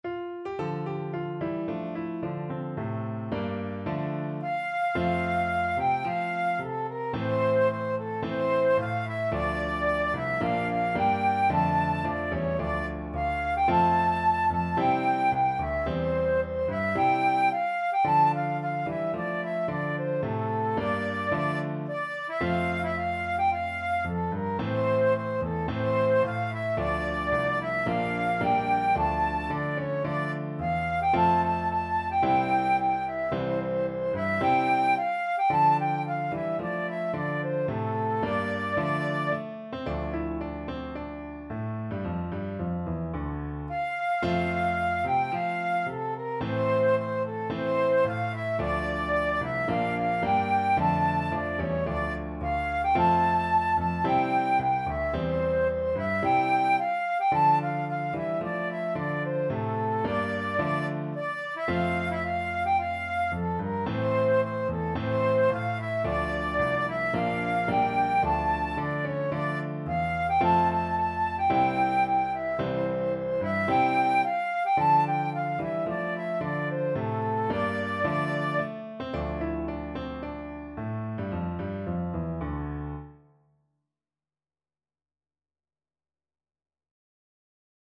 ~ = 110 Allegro (View more music marked Allegro)
4/4 (View more 4/4 Music)
Traditional (View more Traditional Flute Music)